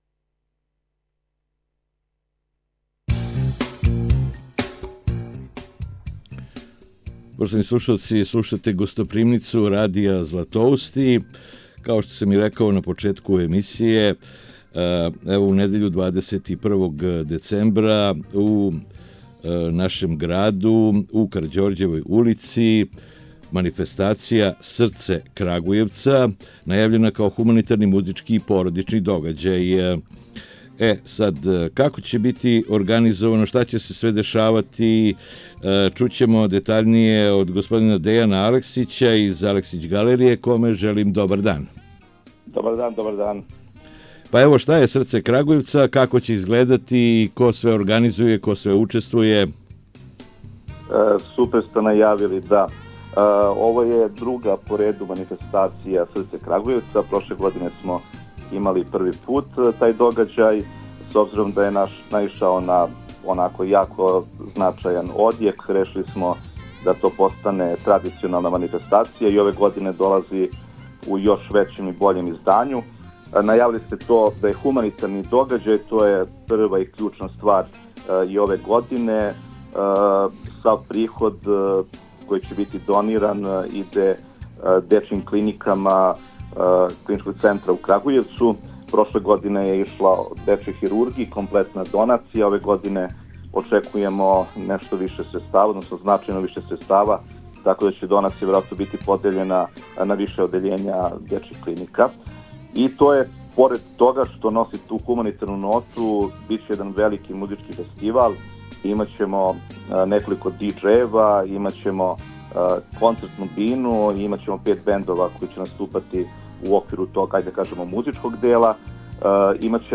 О овој хуманитарној манифестацији у „Гостопримници“ разговарамо